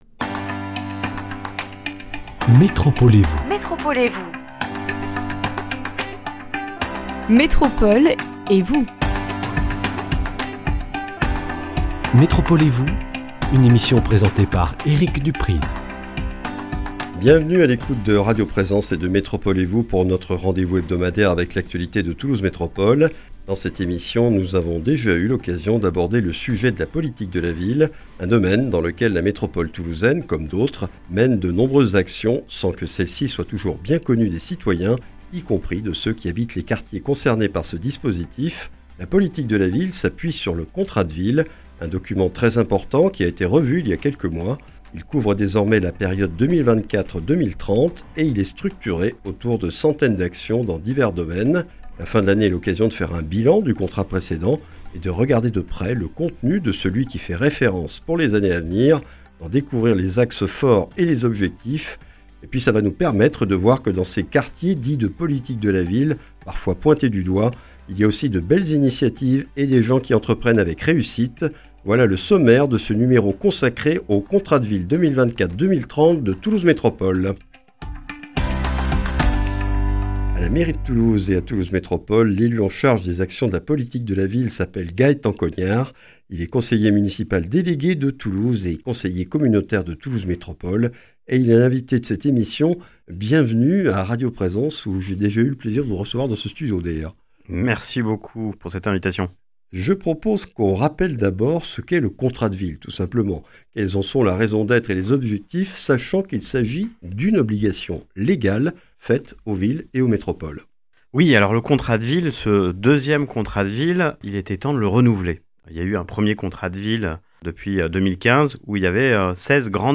Gaëtan Cognard, conseiller municipal délégué de Toulouse et conseiller communautaire de Toulouse Métropole, est l’invité de ce numéro pour présenter le Contrat de ville 2024-2030 de Toulouse Métropole. Un document élaboré avec les citoyens et structuré autour d’actions et dispositifs visant à réduire les inégalités sociales dans 16 quartiers métropolitains et à soutenir les initiatives de leurs habitants.